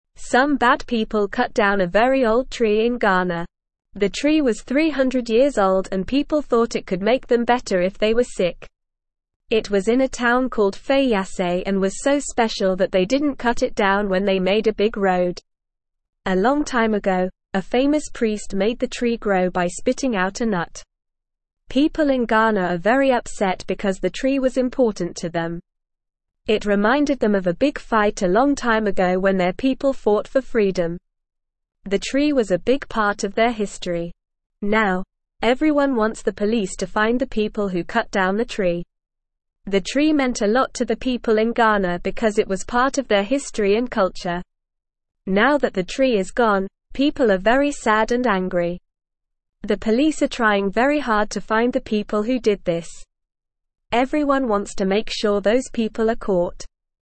Normal
English-Newsroom-Lower-Intermediate-NORMAL-Reading-Old-Tree-in-Ghana-Cut-Down-by-Bad-People.mp3